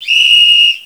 Listen to a whistle. 2.
whistle.wav